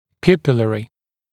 [‘pjuːpɪlərɪ][‘пйу:пилэри]зрачковый, пупиллярный